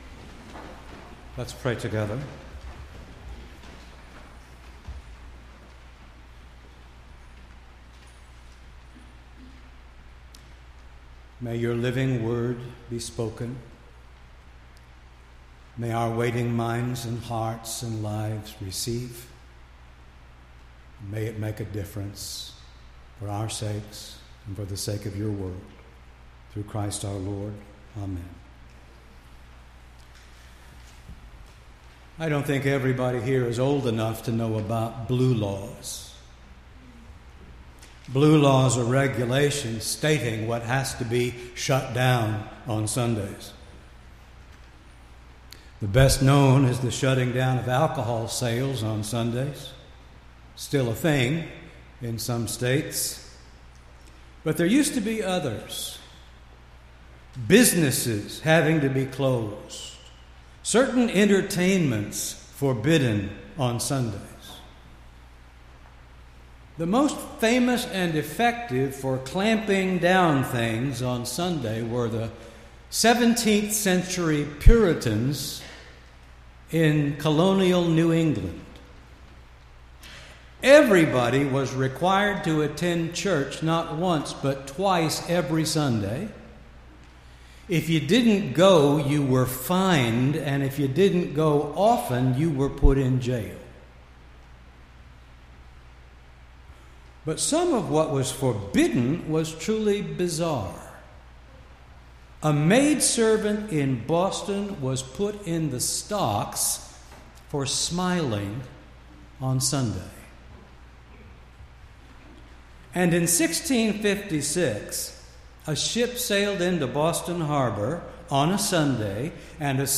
6-3-18-sermon.mp3